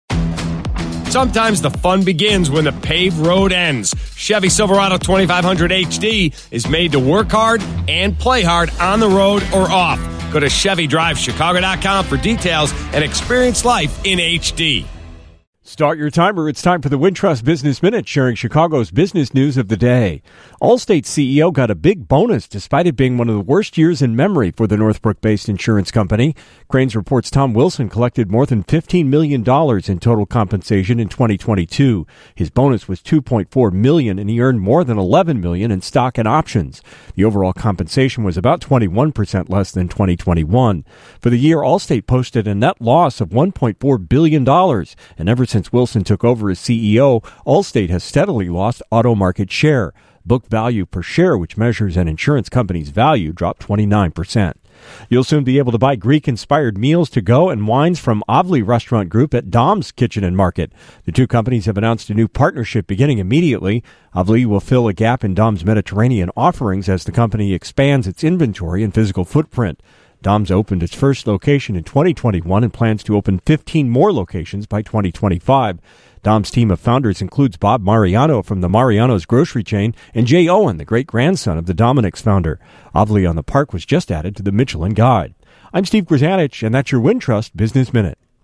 has the business news of the day with the Wintrust Business Minute.